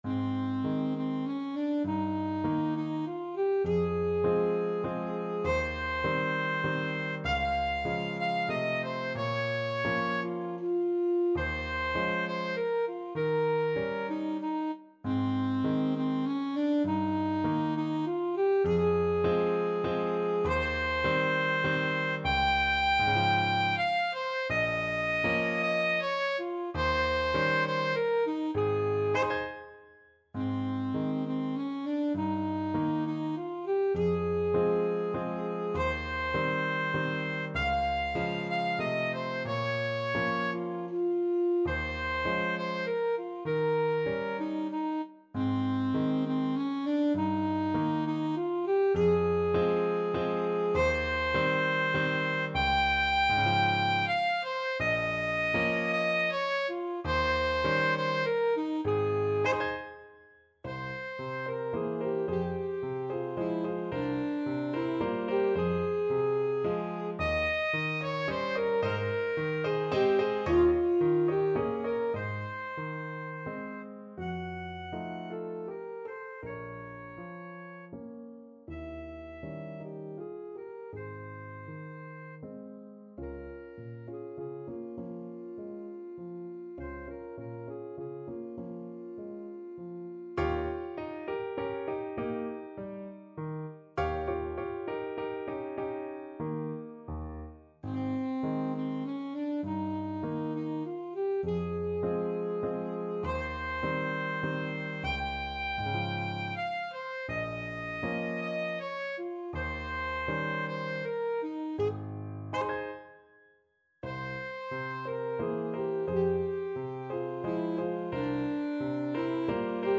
Classical Merikanto, Oskar Valse lente, Op.33 Alto Saxophone version
3/4 (View more 3/4 Music)
Ab major (Sounding Pitch) F major (Alto Saxophone in Eb) (View more Ab major Music for Saxophone )
~ = 100 Tranquillamente
Classical (View more Classical Saxophone Music)